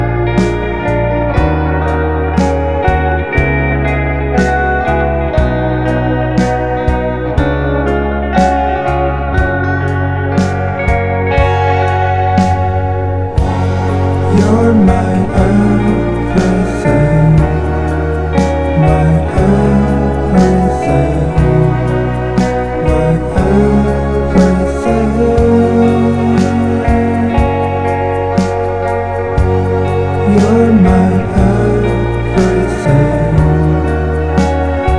Жанр: Иностранный рок / Рок / Инди
# Indie Rock